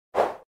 Системные звуки Apple iMac и MacBook Pro и Air в mp3 формате